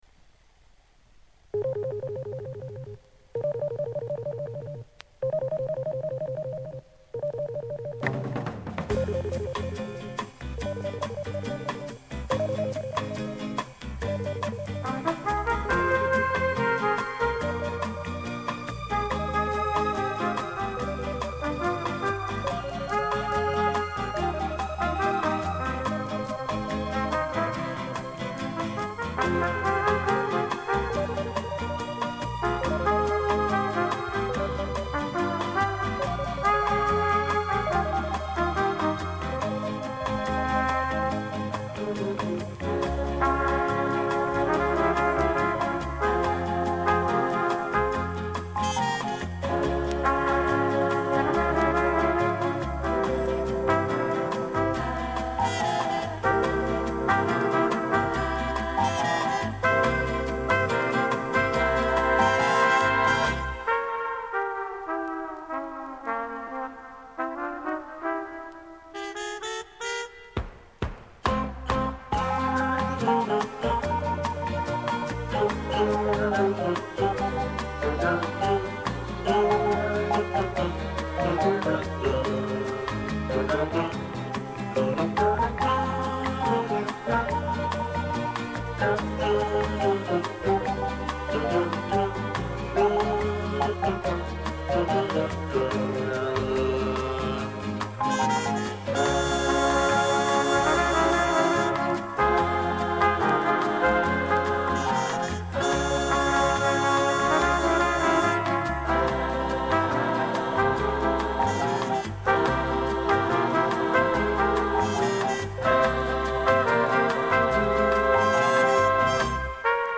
Музыкант-трубач из Чехословакии